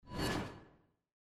pointer_sound.mp3